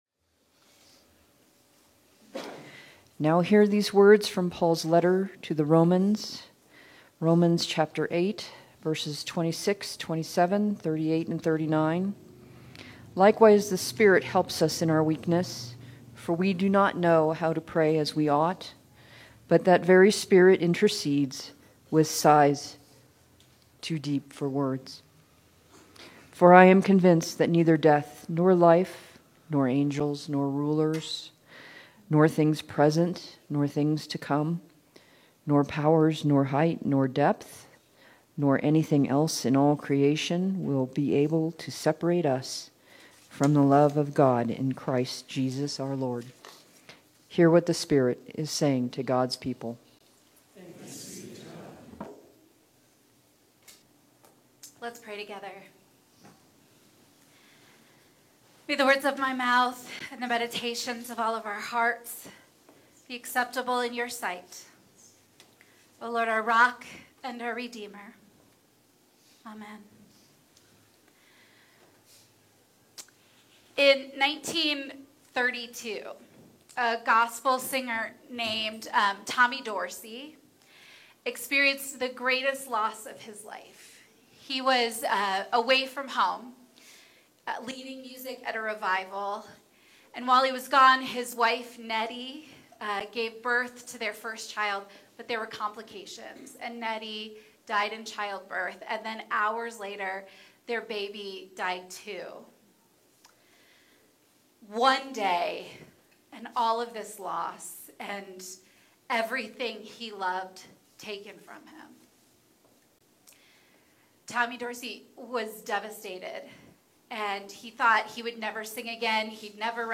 Sermons | United Methodist Church of Evergreen